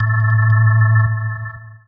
orgTTE54027organ-A.wav